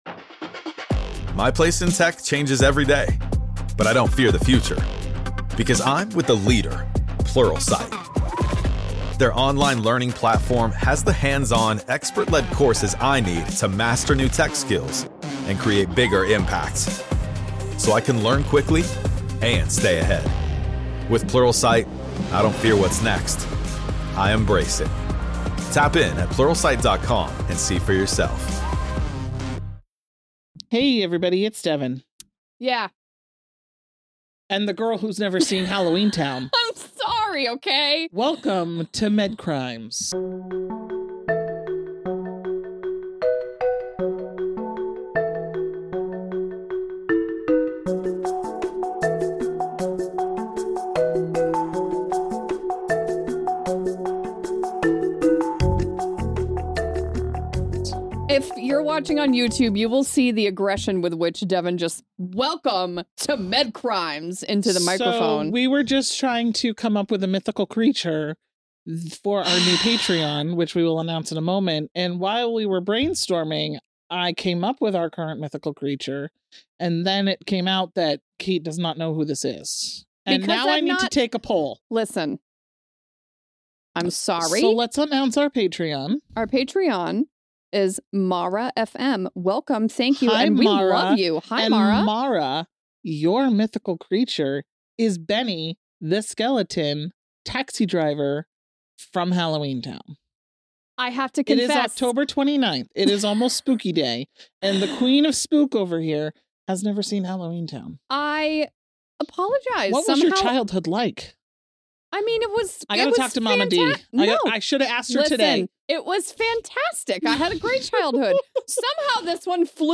The hosts are two best friends who are chatting about true crime cases in the medical field.